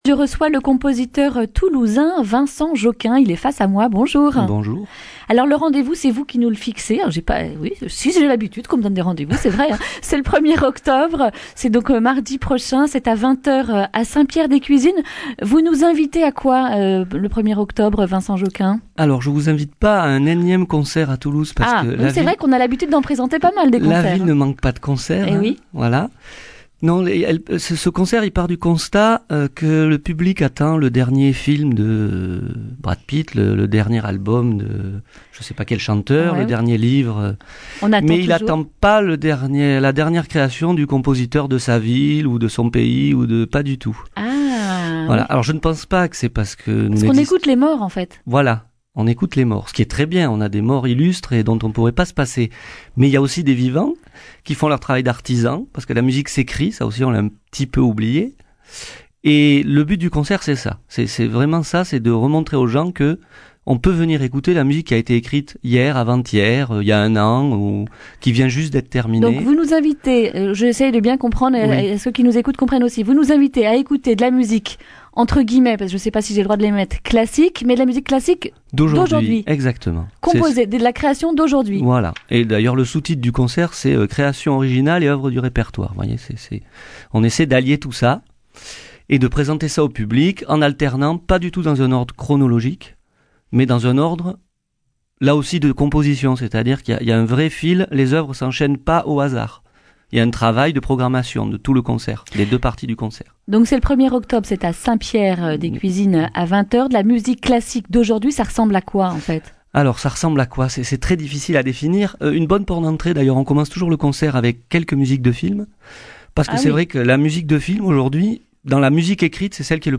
jeudi 26 septembre 2019 Le grand entretien Durée 11 min